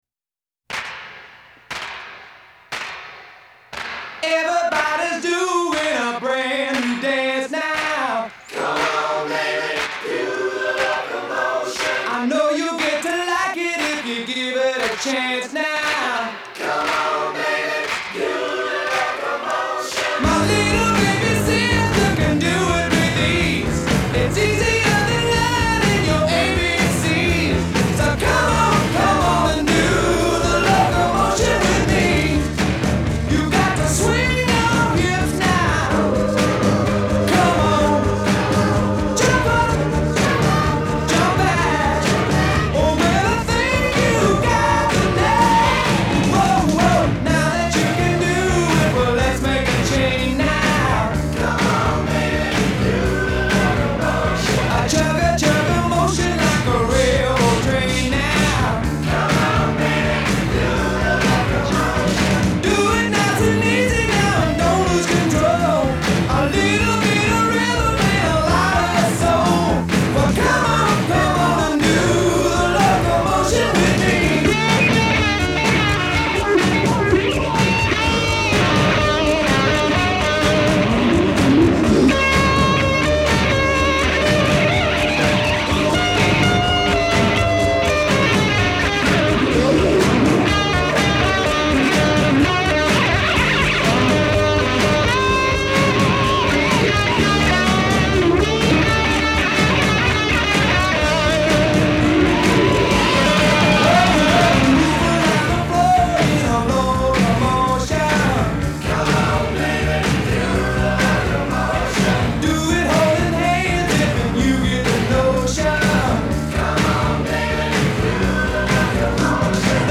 американская хард-рок-группа